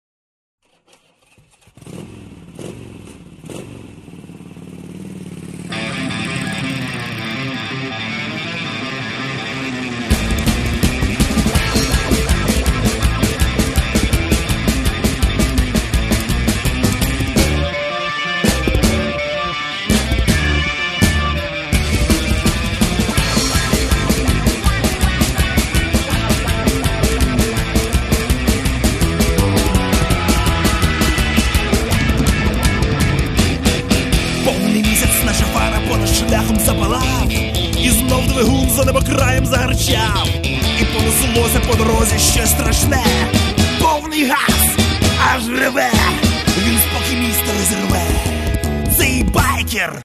Home » CDs» Rock My account  |  Shopping Cart  |  Checkout